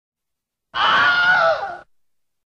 movie scream sound effects
movie-scream